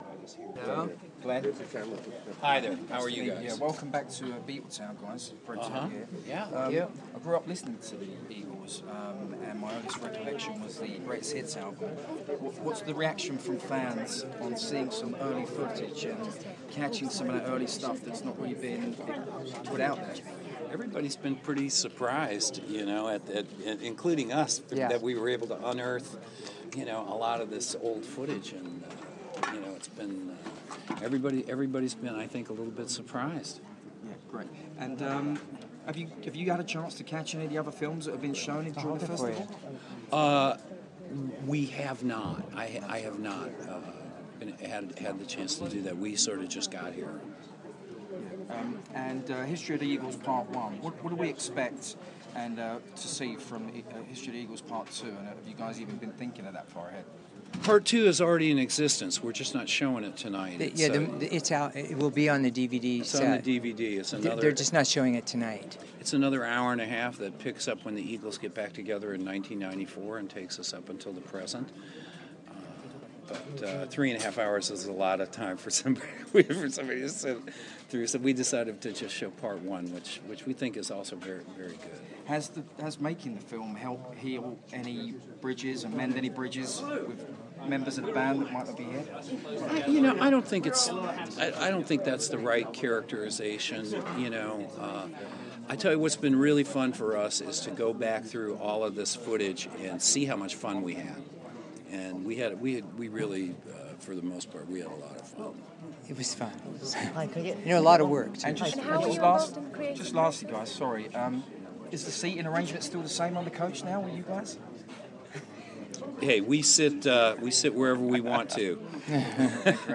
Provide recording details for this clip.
The Eagles talking at Sundance London